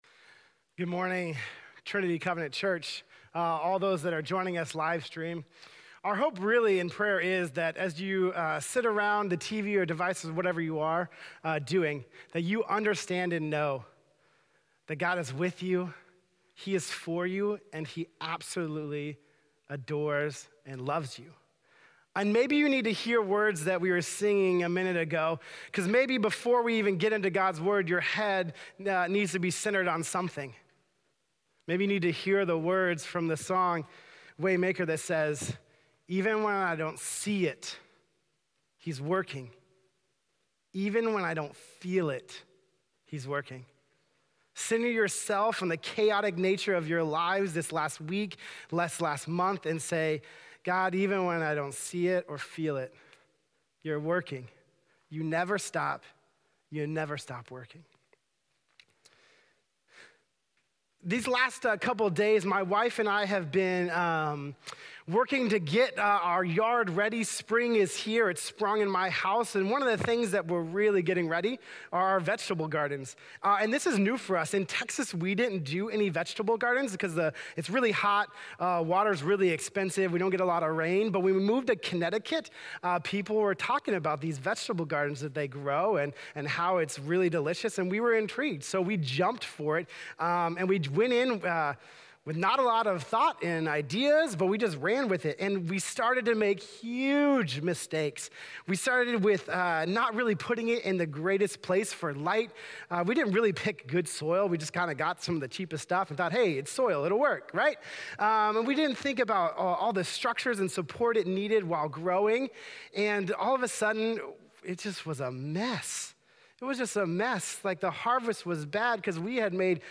Online Worship Previous Messages